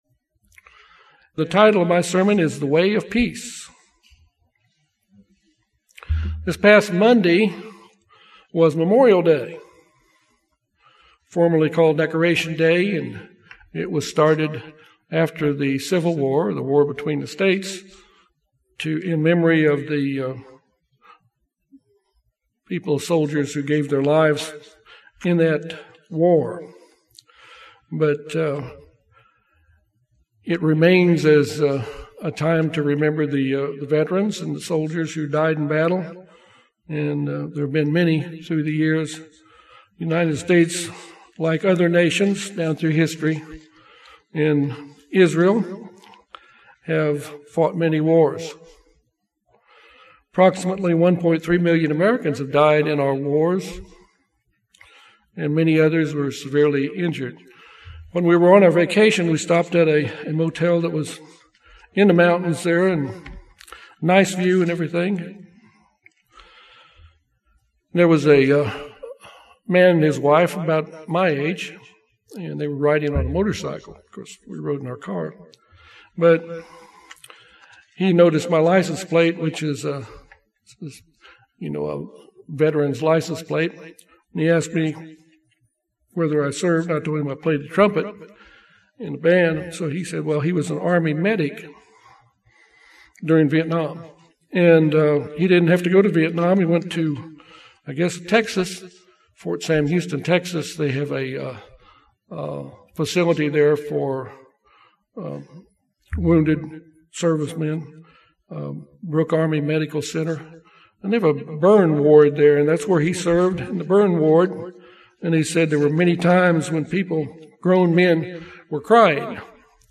This sermon discusses the causes of war and fighting and what scripture states is the way that will result in peace.
Given in Little Rock, AR